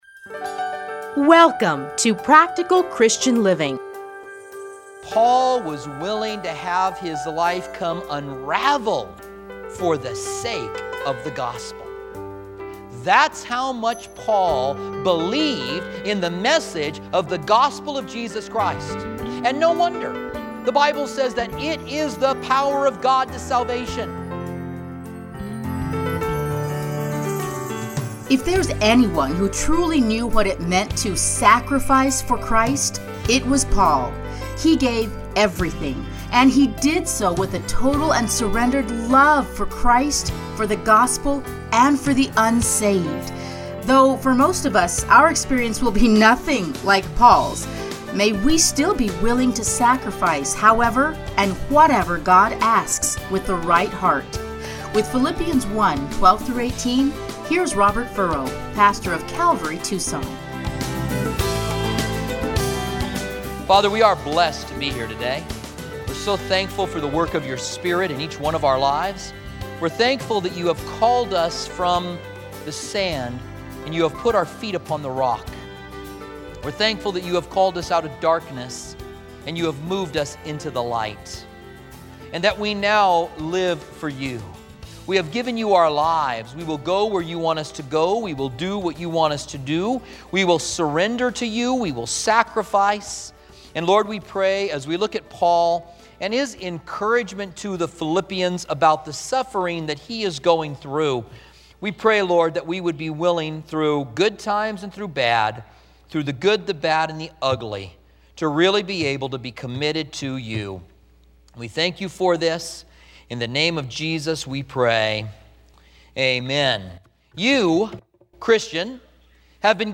Listen here to his commentary on Philippians.